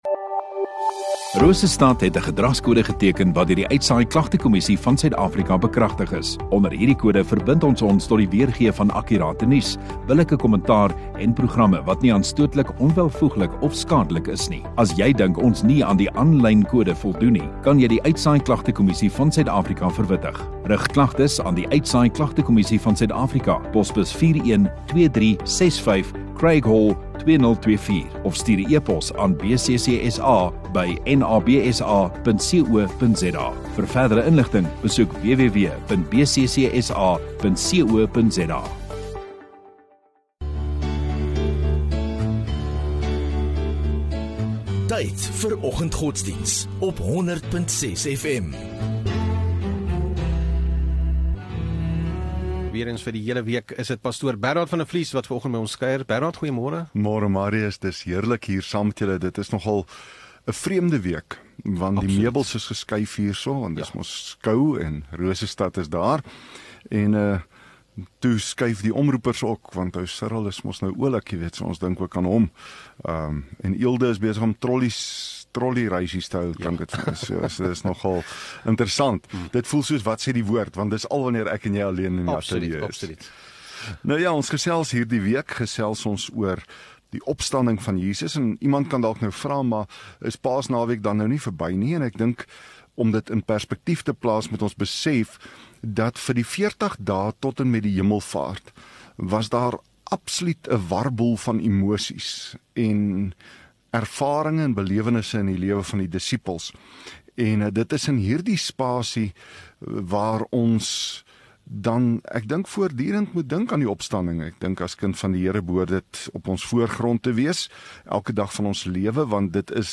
24 Apr Woensdag Oggenddiens